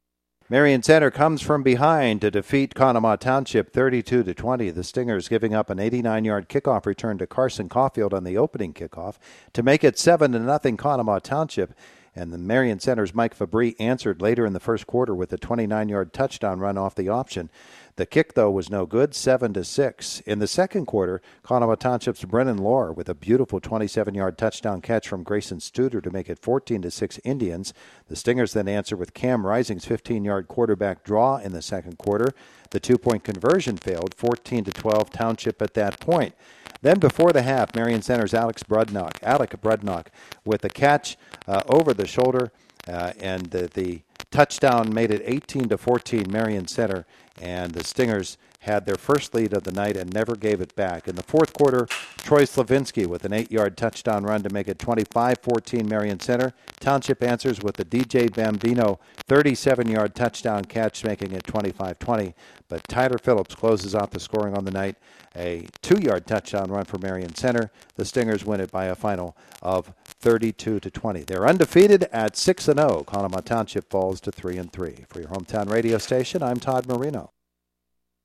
hsfb-conemaugh-township-marion-center-recap.mp3